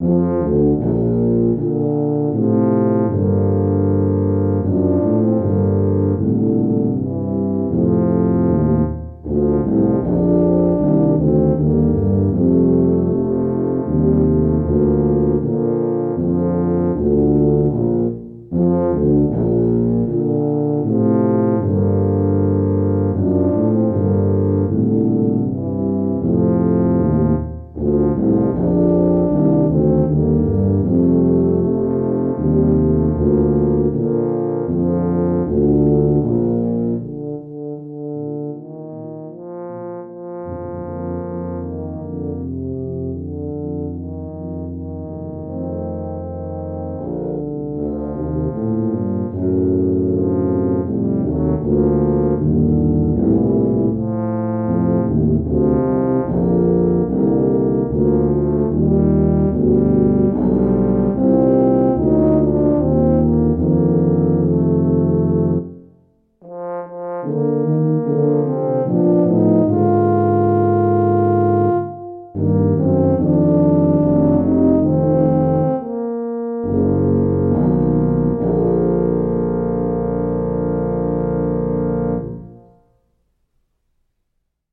Voicing: Tuba 4